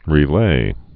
(rē-lā)